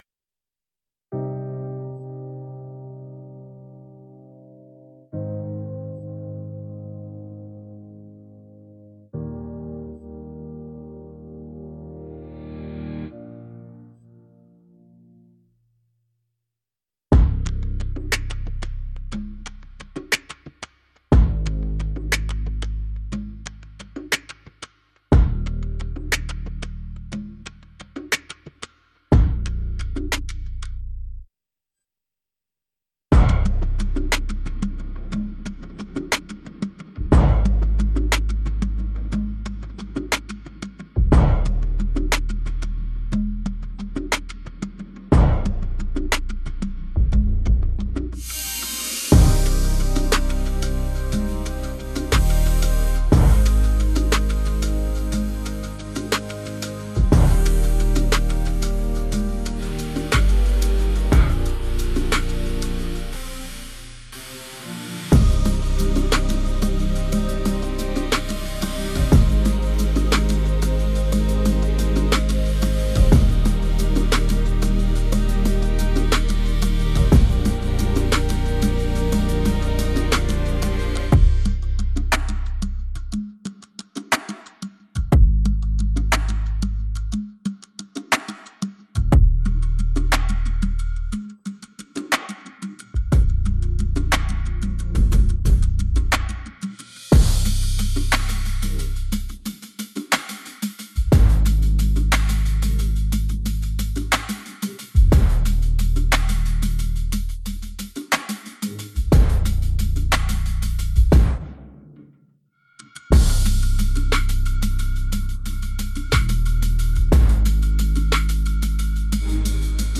is a soul-lifting musical journey designed to inspire faith